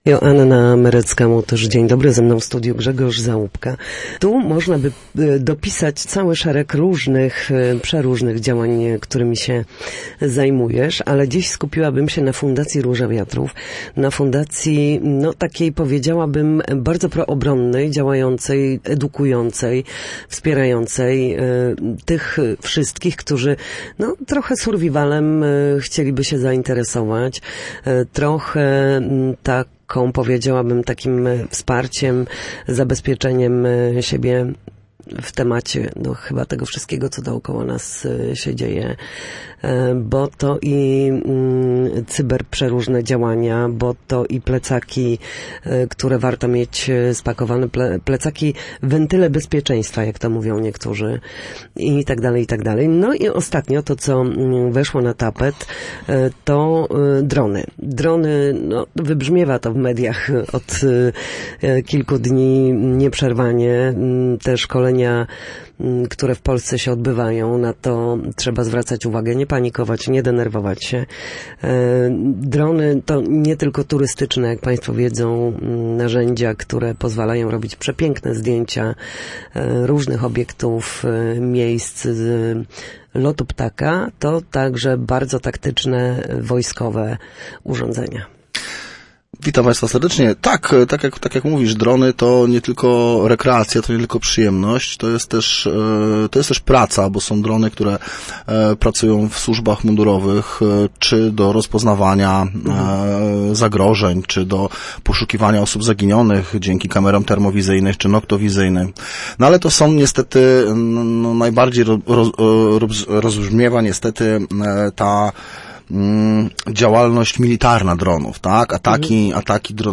Na naszej antenie mówił o organizowanych warsztatach z obsługi dronów.